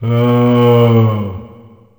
c_zombim2_atk1.wav